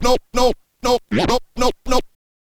scratch_kit01_09.wav